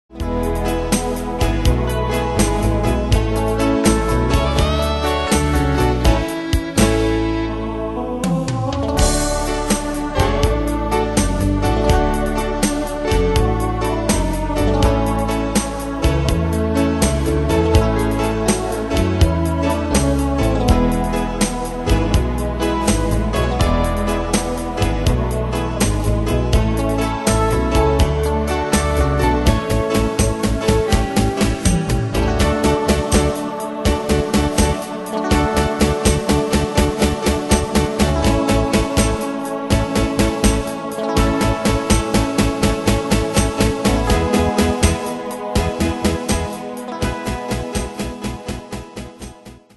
Style: Oldies Ane/Year: 1958 Tempo: 82 Durée/Time: 2.25
Danse/Dance: Ballade Cat Id.
Pro Backing Tracks